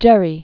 (gĕrē), Elbridge 1744-1814.